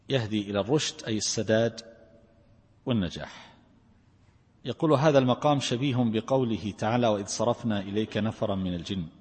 التفسير الصوتي [الجن / 2]